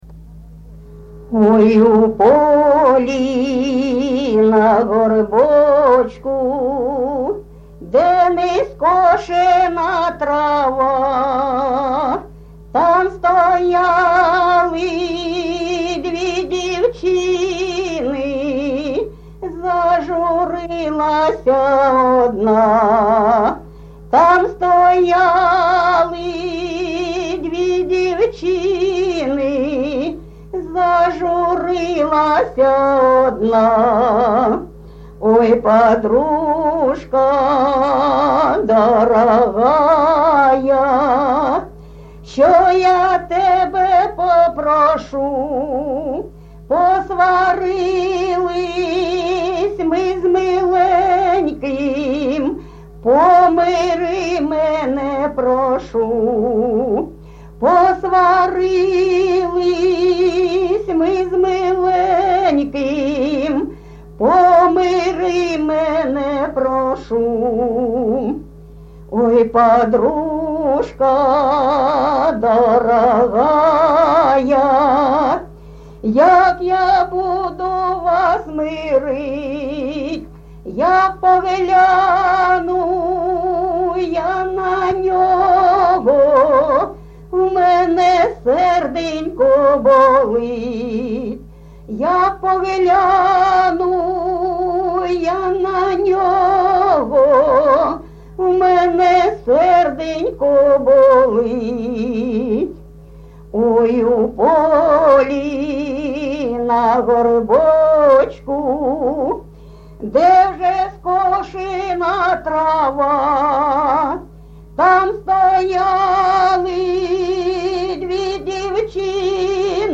ЖанрПісні з особистого та родинного життя
МотивНещасливе кохання, Журба, туга
Місце записум. Часів Яр, Артемівський (Бахмутський) район, Донецька обл., Україна, Слобожанщина